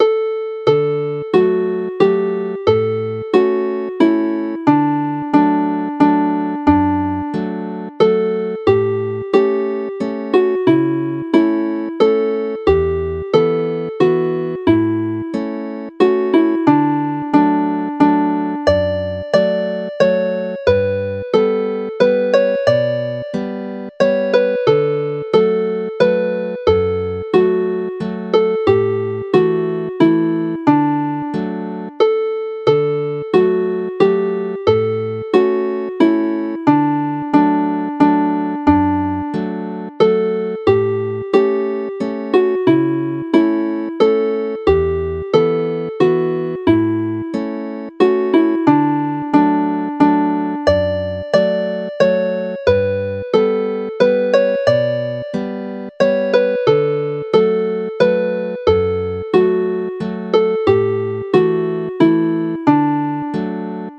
Chwarae'r alaw'n araf
Play the melody slowly